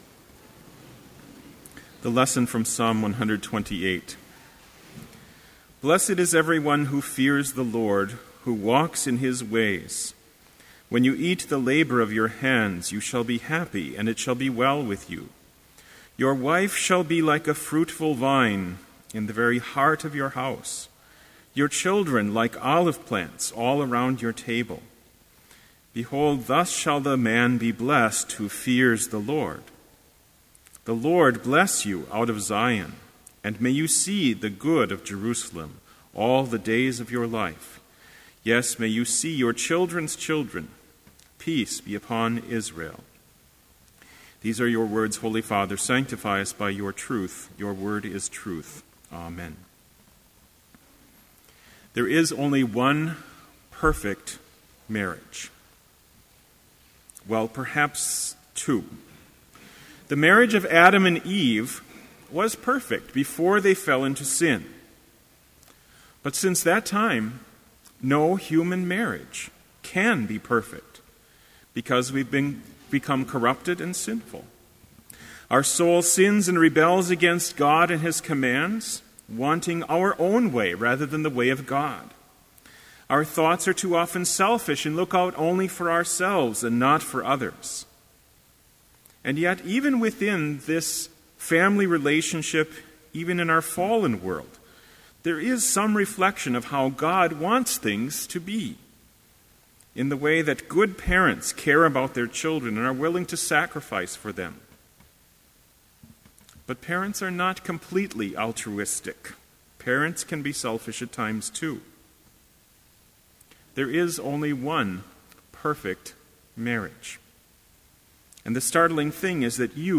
Complete Service
• Homily
This Chapel Service was held in Trinity Chapel at Bethany Lutheran College on Wednesday, January 23, 2013, at 10 a.m. Page and hymn numbers are from the Evangelical Lutheran Hymnary.